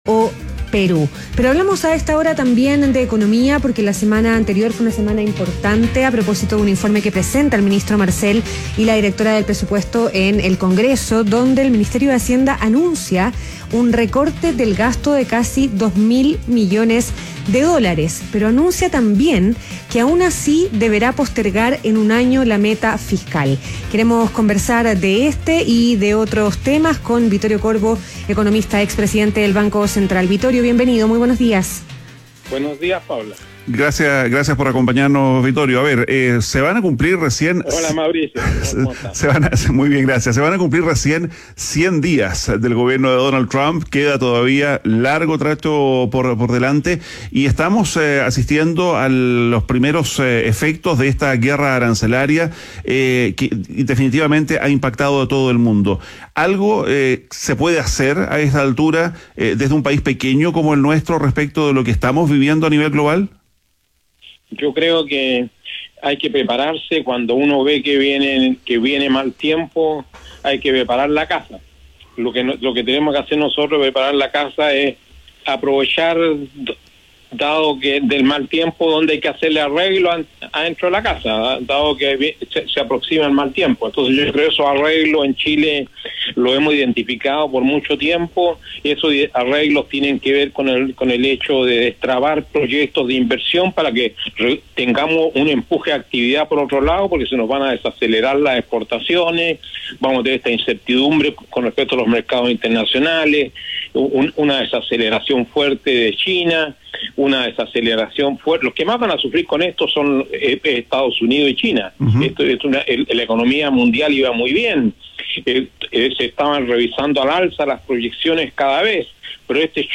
ADN Hoy - Entrevista a Vittorio Corbo, expresidente del Banco Central